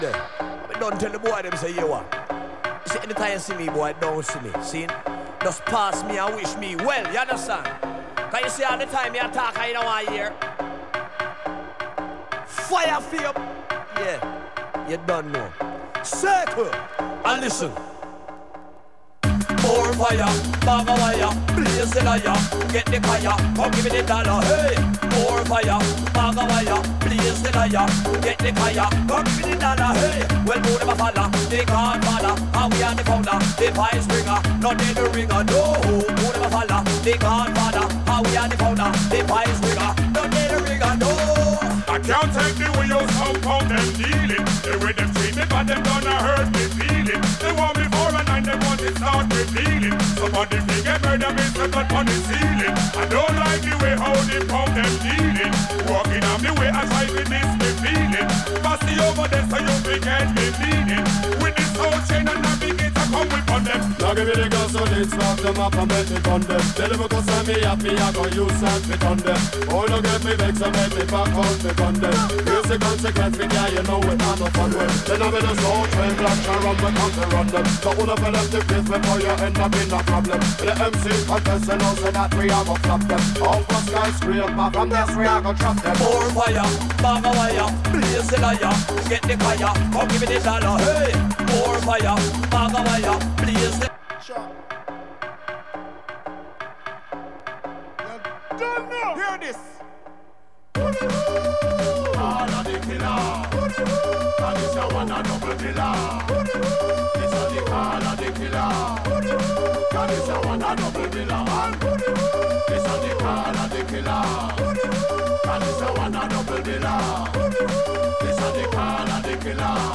Drum N Bass , Jungle